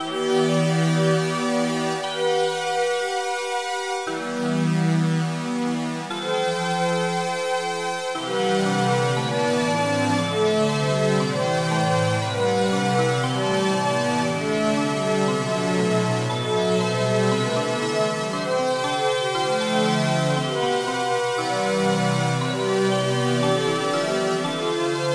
Žánr : elektronická hudba
Synth New Age,Soundtrack